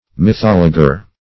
Mythologer \My*thol"o*ger\, n.